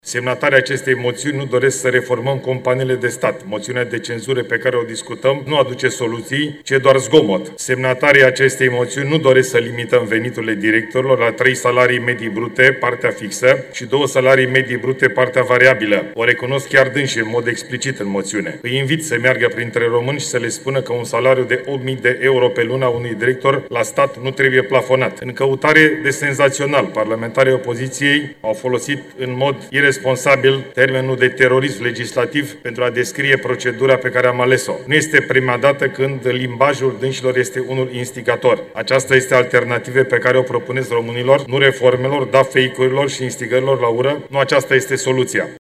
Semnatarii acestei moțiuni nu doresc să reformăm companiile de stat, a fost replica premierului Ilie Bolojan.